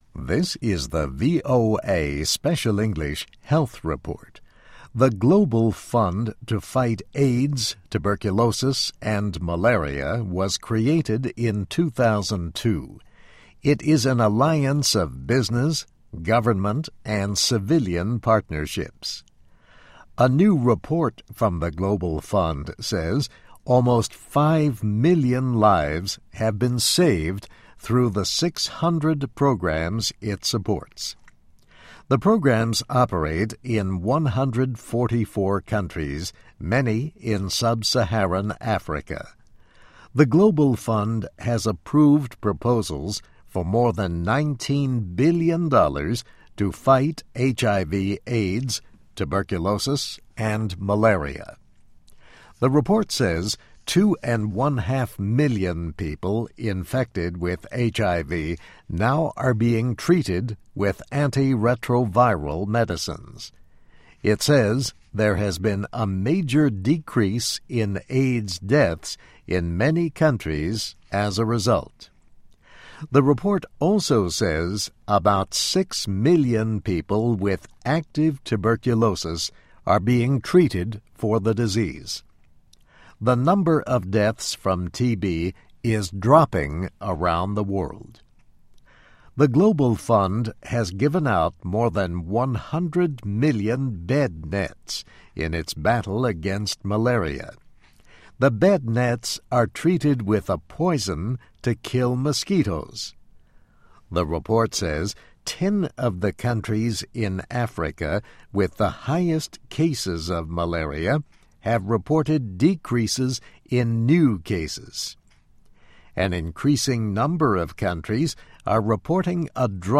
VOA Special English - Text & MP3